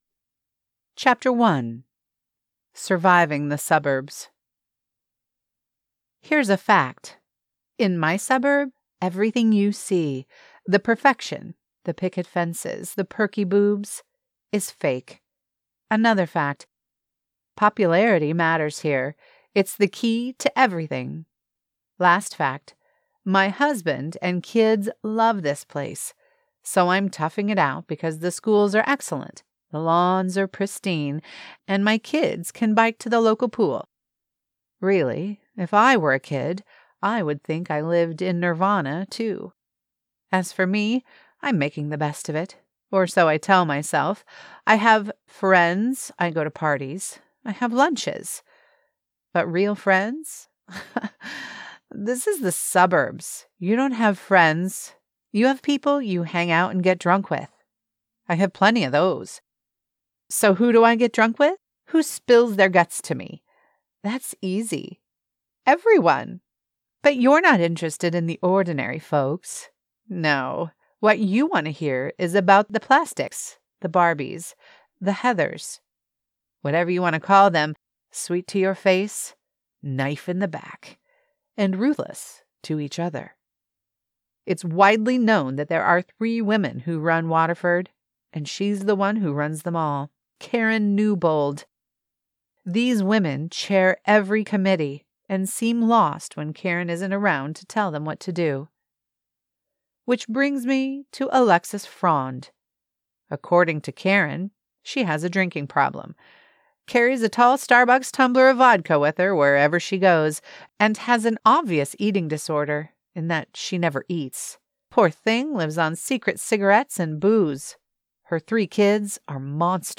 AUDIOBOOKS